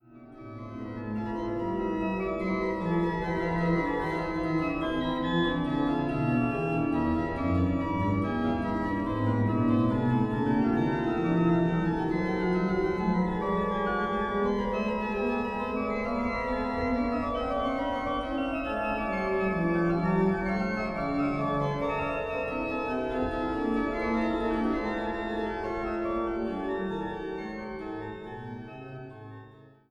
Walcker-Orgel